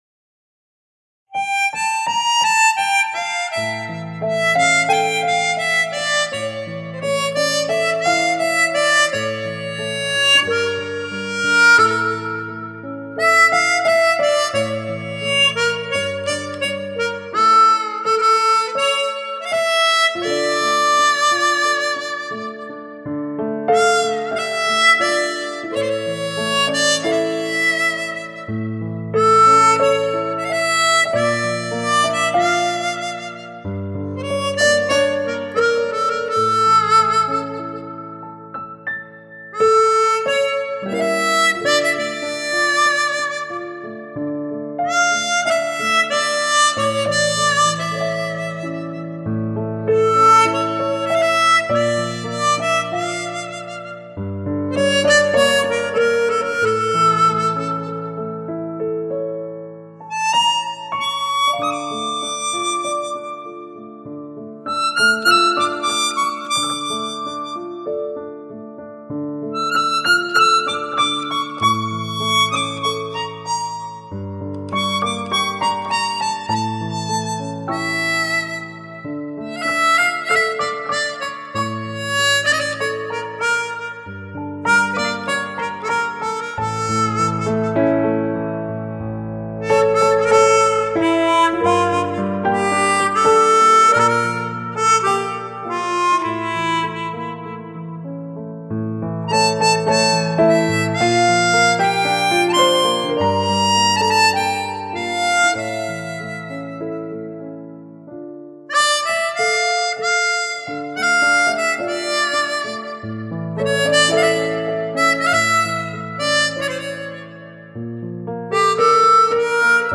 ملودی ساده اما تاثیرگذار آن نیز بر عمق احساس آهنگ می‌افزاید.